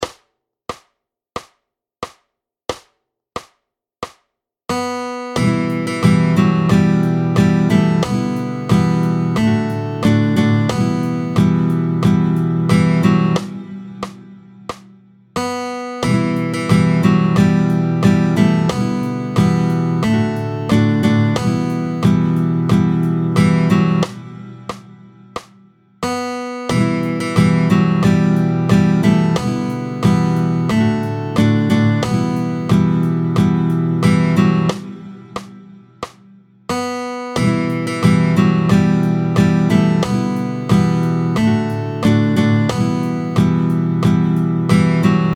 tempo 90, binaire
est binaire, composé en 4/4 (également mesure à 4 temps).